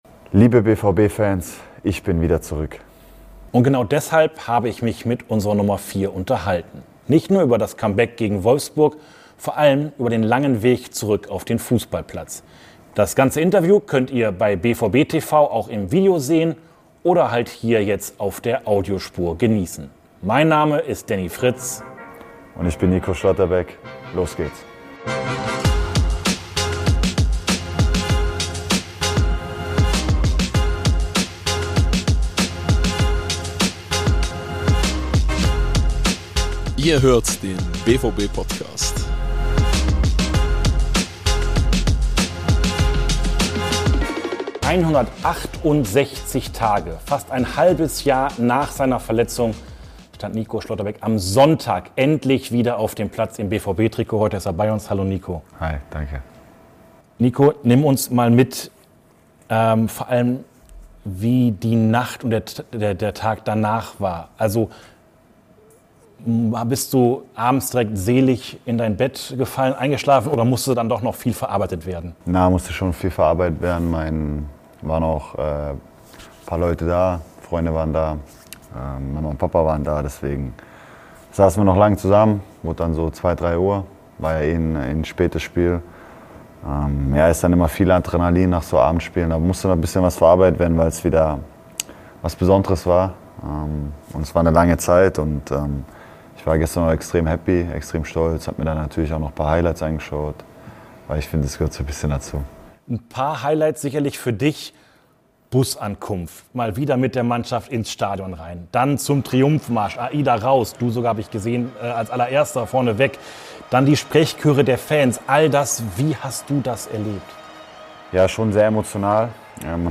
Schlotti: Mein Weg zurück. Das große Comeback-Interview ~ Borussia Dortmund Podcast
Im Interview nach seinem Comeback nimmt uns der Innenverteidiger mit auf die Reise von der Verletzung über seine Reha bis zur Rückkehr in die Startelf. Warum Kapitän Emre Can ein großer Halt für ihn war, wem gegenüber er irgendwann ungemütlich geworden ist und warum er jetzt höchsten Respekt vor jedem Spieler in der Reha hat, verrät er im BVB-Podcast.